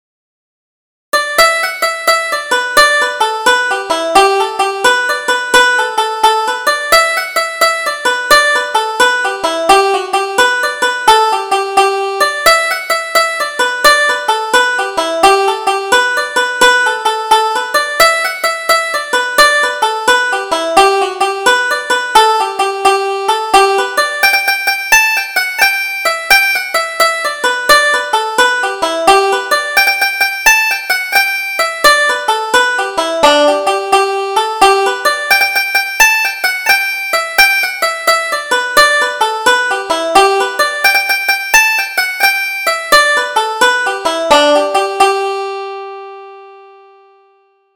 Double Jig: The Woeful Widow